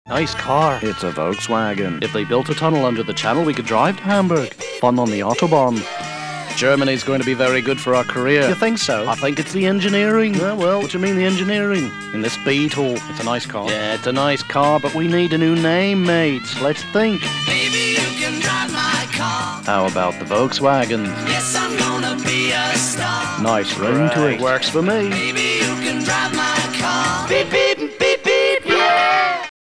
Characters On Spec National Radio Voices/writer